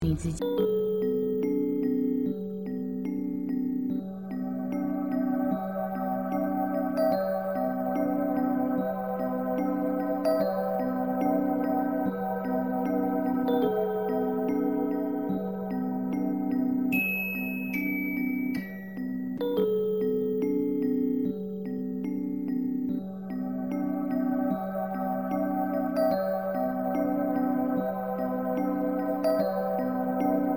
催眠音-试听
sleep-2.mp3